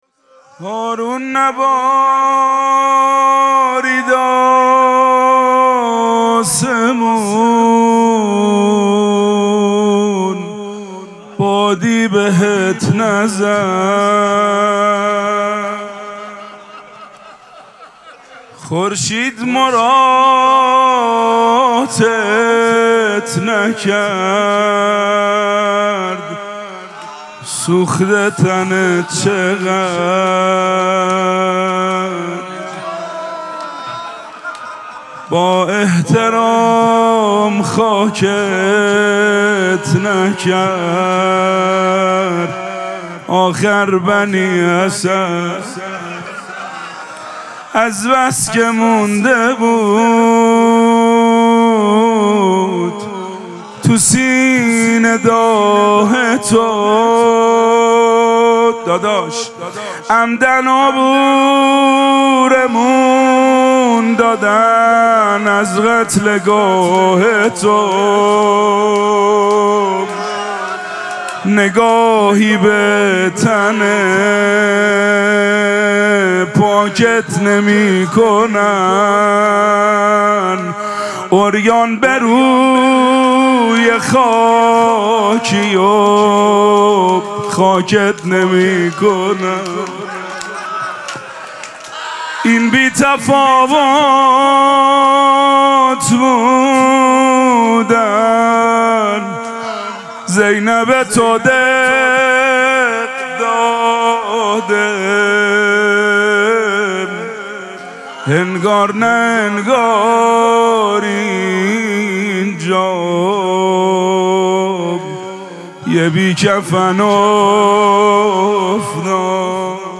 مراسم مناجات شب سیزدهم ماه مبارک رمضان
روضه